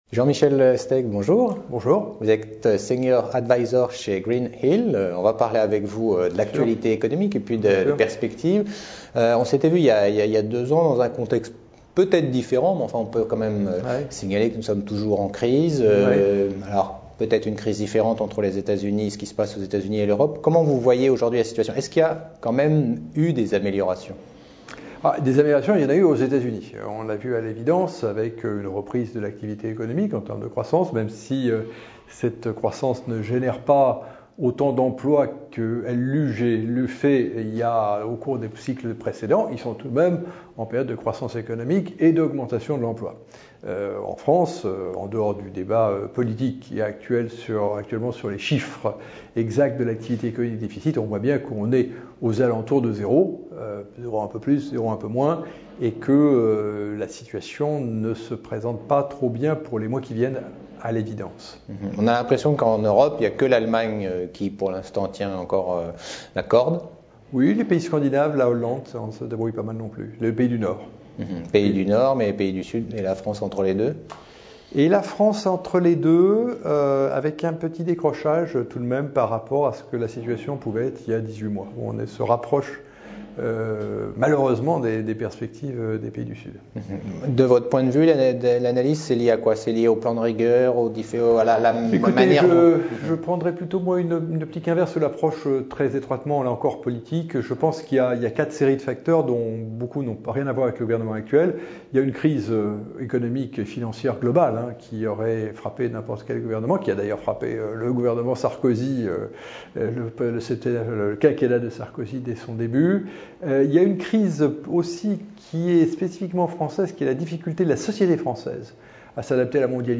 L’interview complète :